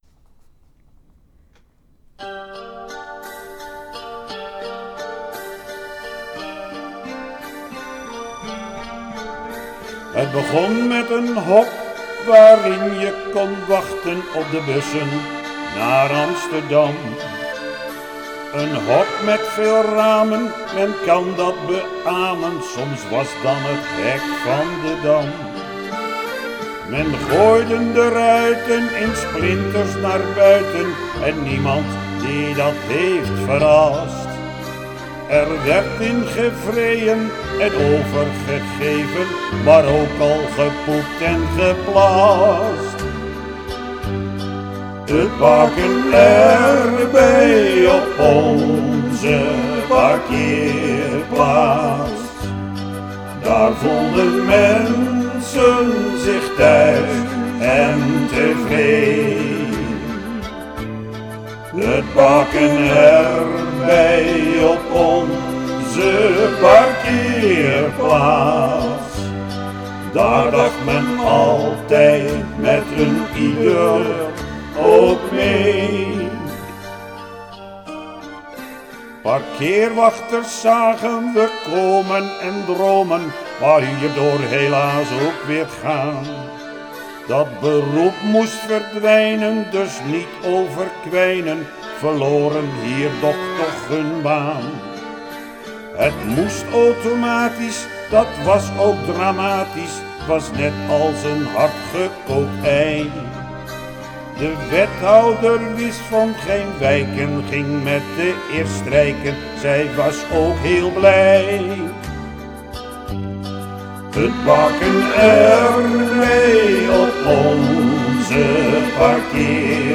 Inclusief afscheidslied
Hij heeft het ook zelf ingezongen